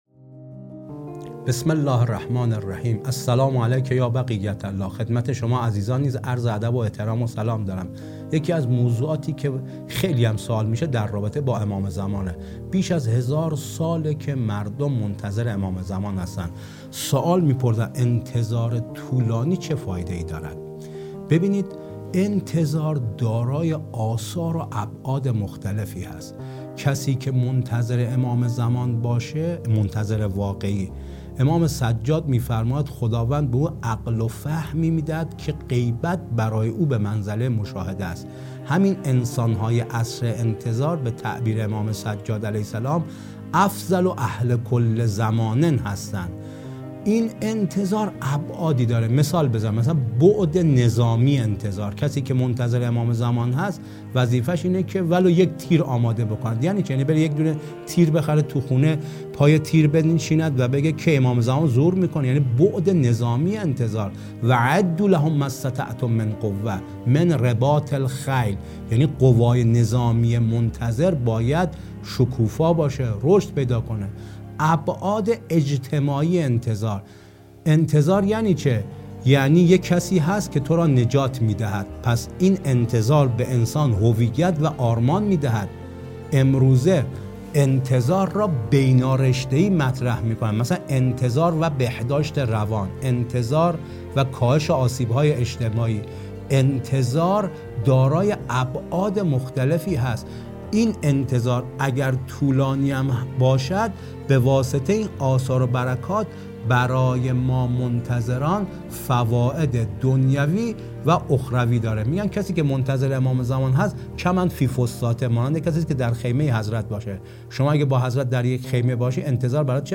گفتگویی تخصصی